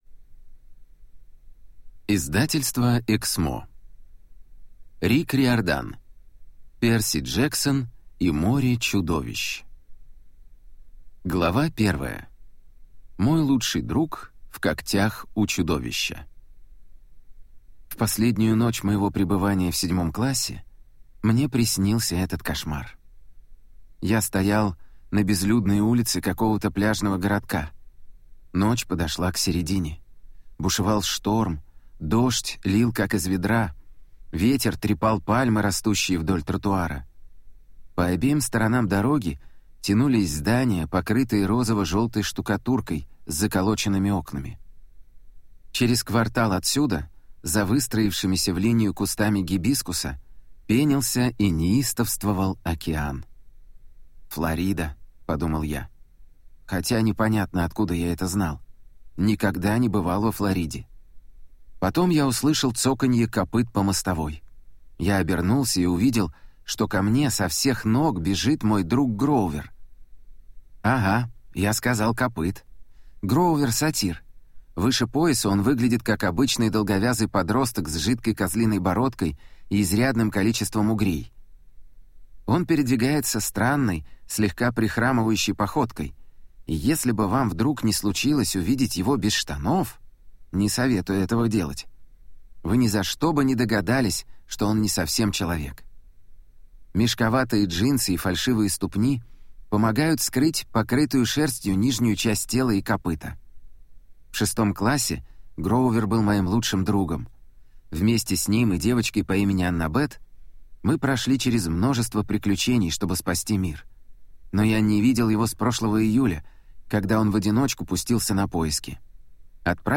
Аудиокнига Перси Джексон и море Чудовищ | Библиотека аудиокниг